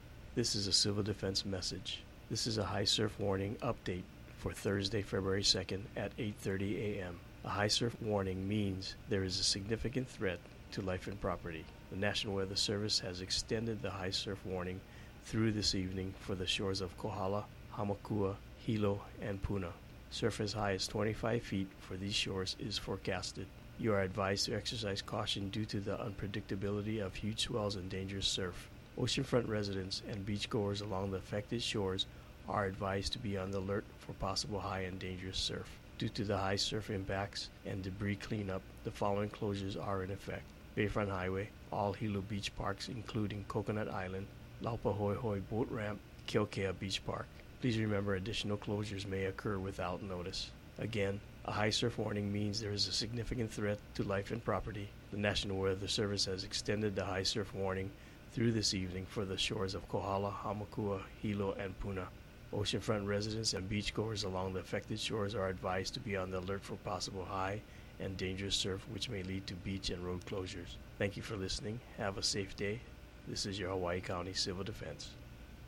Hawaii County Civil Defense audio message